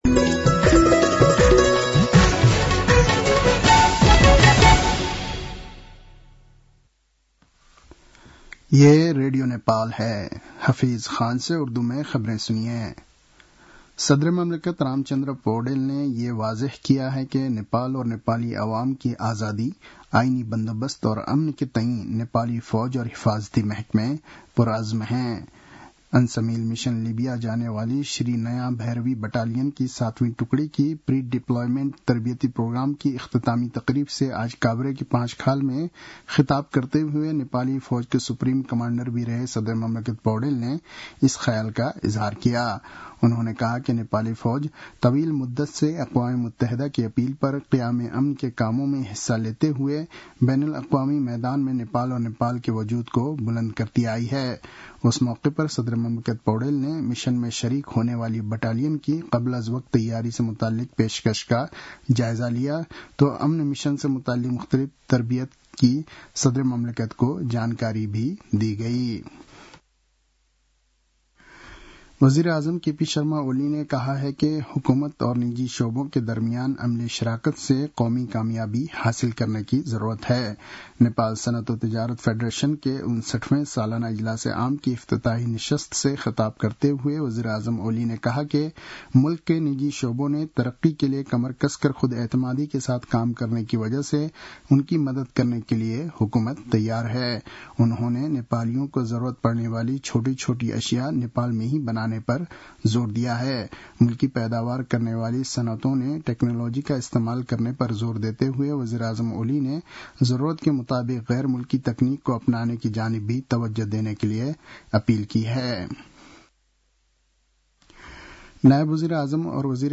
उर्दु भाषामा समाचार : २८ चैत , २०८१